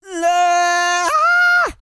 E-CROON 3030.wav